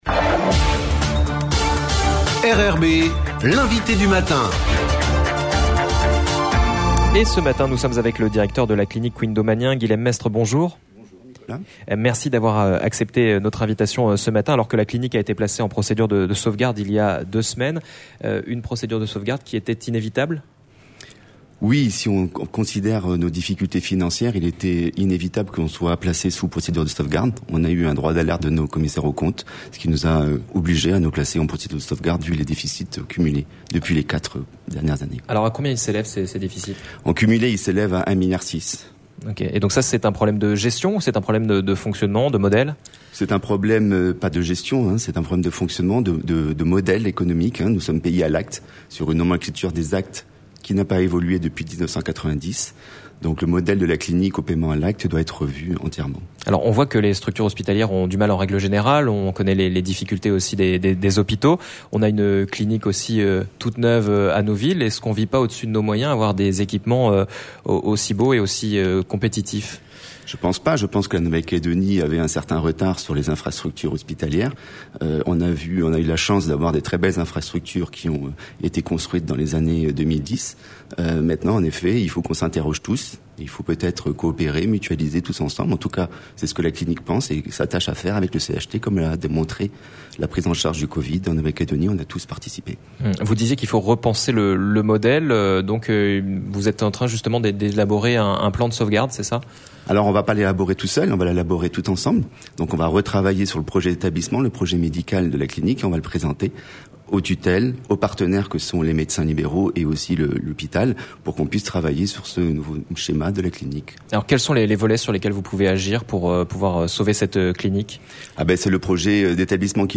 L'INVITE DU MATIN